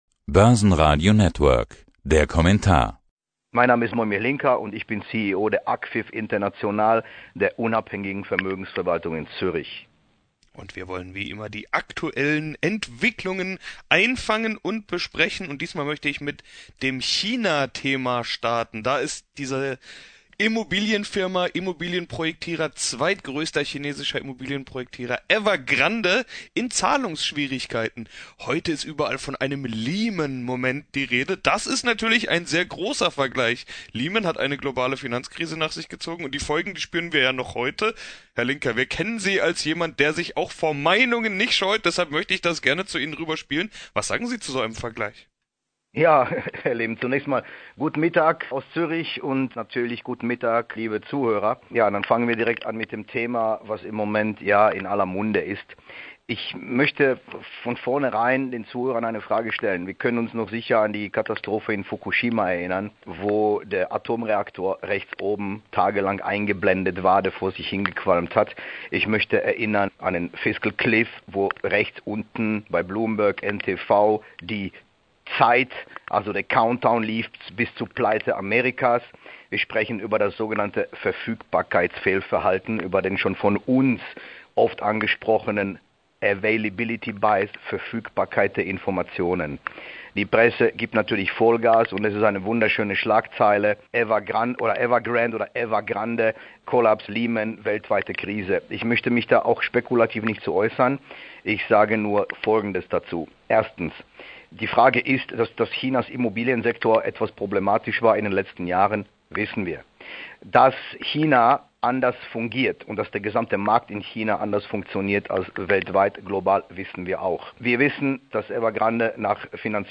Außerdem: wichtige Faktoren für die weitere Entwicklung der Kurse und der Blick ins Börsenradio-Echtgeld-Depot. _______________________________ > HIER auf Börsenradio das Interview hören